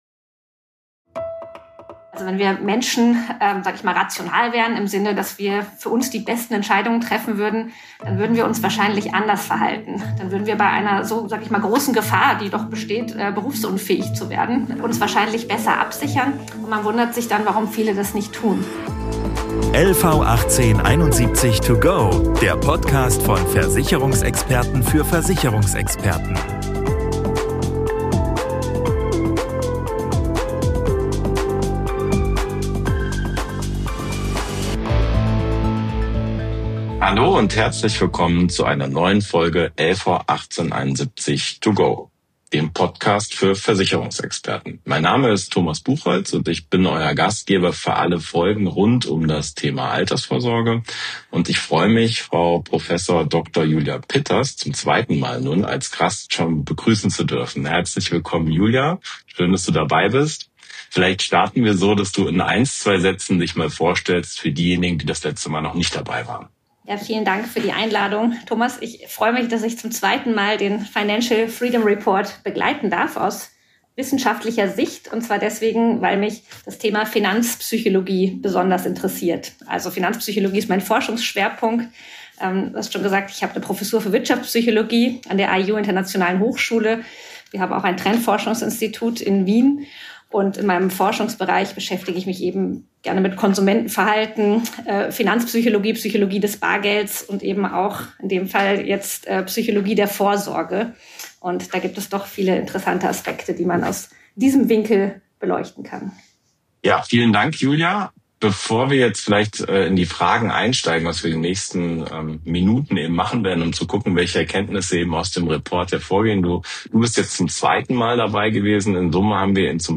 Ein Gespräch über Risikowahrnehmung, Eigenverantwortung und die psychologischen Hintergründe finanzieller Entscheidungen.